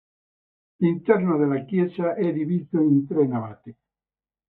Read more Adj Verb Opposite of indiviso Frequency C1 Hyphenated as di‧vì‧so Pronounced as (IPA) /diˈvi.zo/ Etymology Cognate with Piedmontese divis.